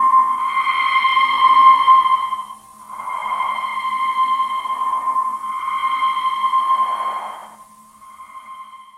Призрачная нота C